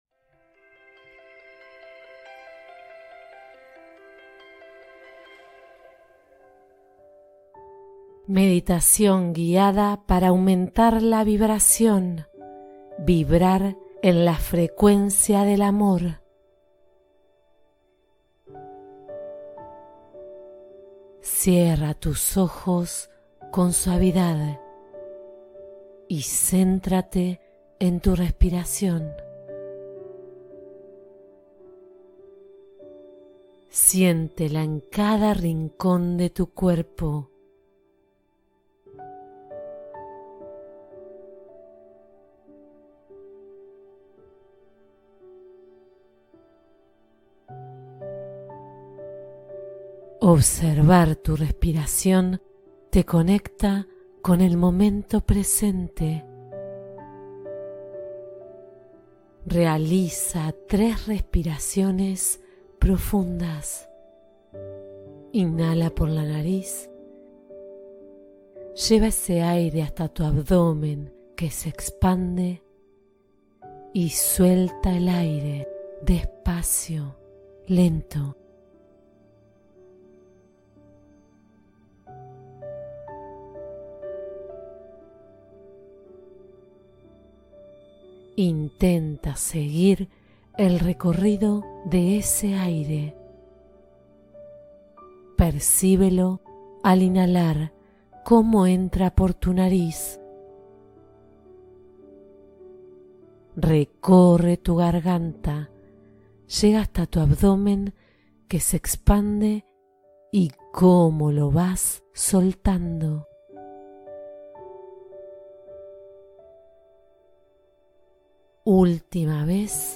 Eleva tu vibración con la energía del amor con esta meditación guiada